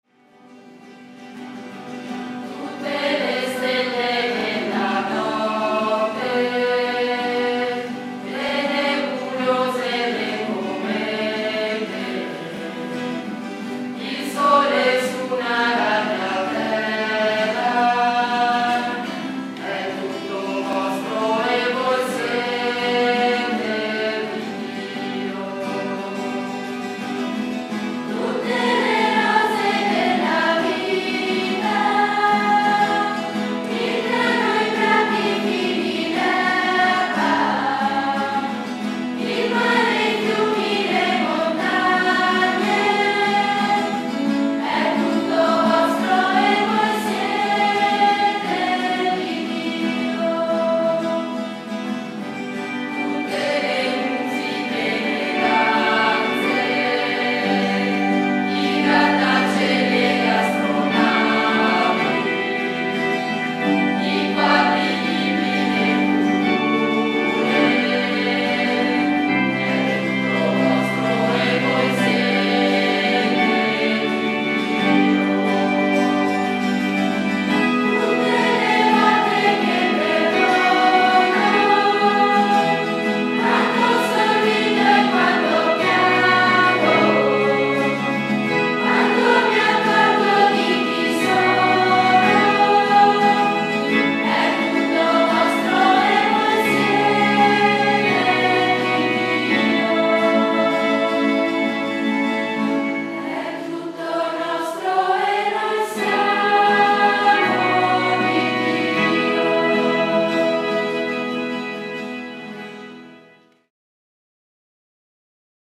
Piccolo coro
E’ il 1996 quando due chitarre si ritrovano in oratorio a Madonna della Salute (di Maser) con un gruppo di bambini per unire musica e parole in preghiera.
E’ così che, col tempo, si cominciò ad abbellire i canti con altre voci, nuove chitarre e un flauto traverso.
A chi è rivolto: Bambini e ragazzi a partire dai 6 anni.